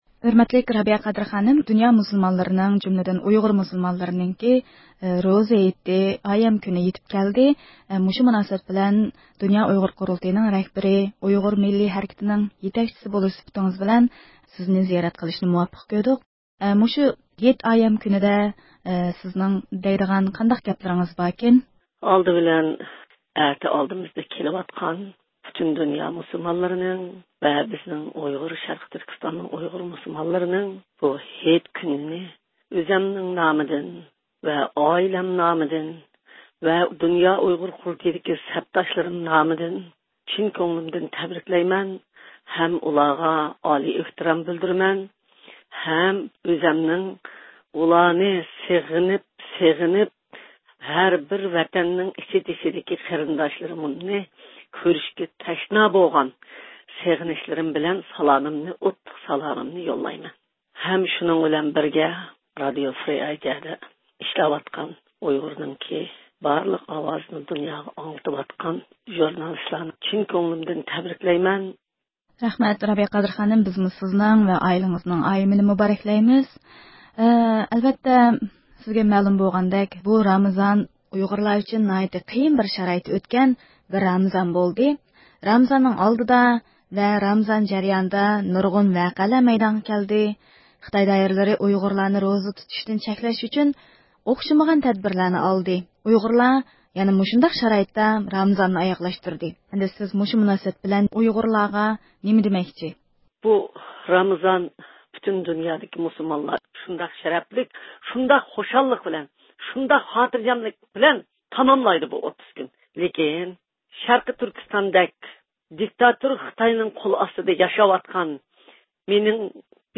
بۇ ئۇلۇغ ئايەم كۈنى مۇناسىۋىتى بىلەن، ئۇيغۇر مىللىي ھەرىكىتى رەھبىرى رابىيە قادىر خانىم رادىئومىز ئارقىلىق ۋەتەن ئىچى ۋە سىرتىدىكى بارلىق ئۇيغۇرلارغا ھېيتلىق سالام يوللىدى.